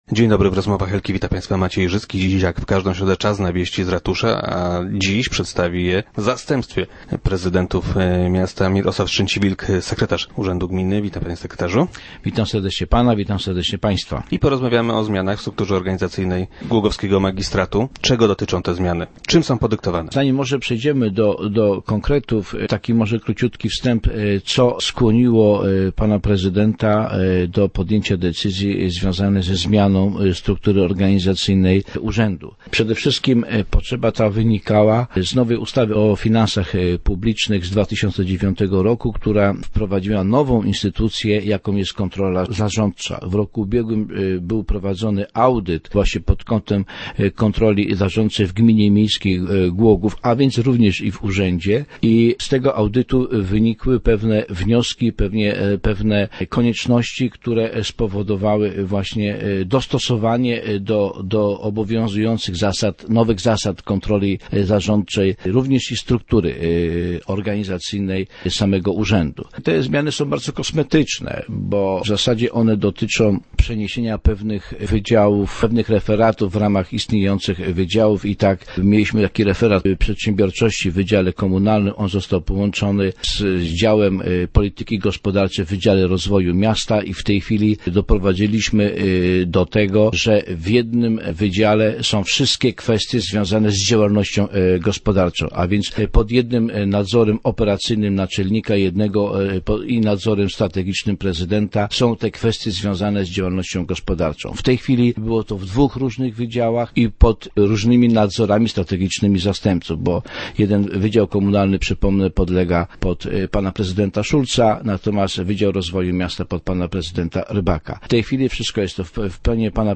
0107_strzeciwilk_do_rozmw.jpgZmieniła się struktura organizacyjna głogowskiego ratusza. - Nie było jednak rewolucji personalnej - zapewnia Mirosław Strzęciwilk, sekretarz gminy miejskiej, który był dziś gościem Rozmów Elki.